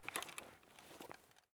sounds / weapons / rattle / raise